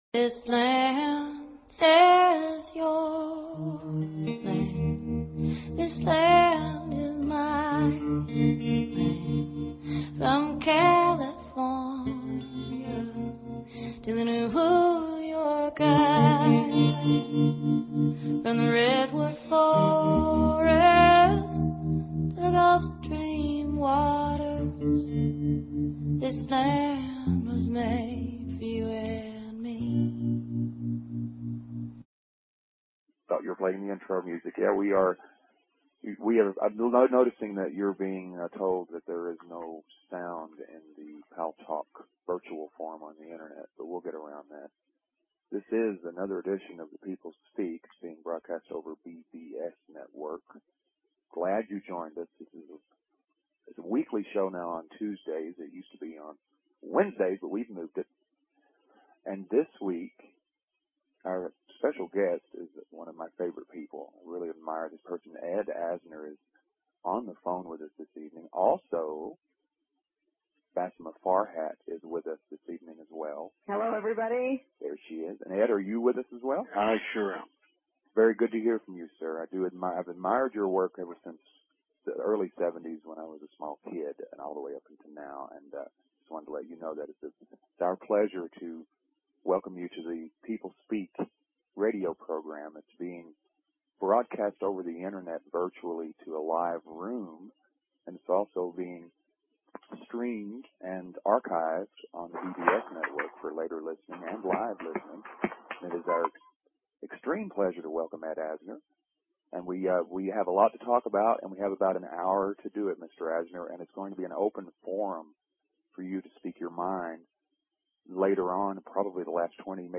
Talk Show Episode, Audio Podcast, The_People_Speak and Ed Asner on , show guests , about , categorized as